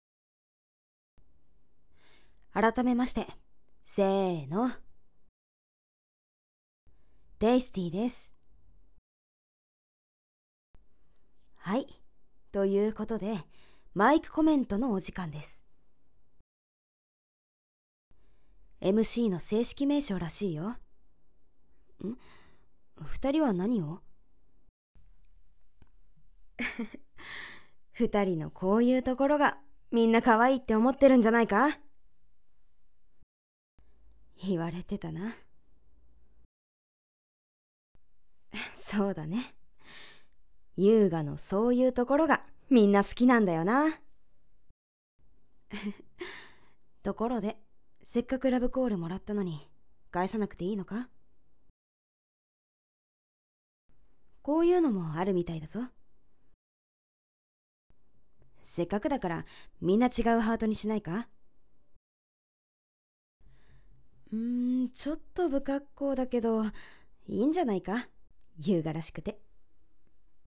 声劇リテイク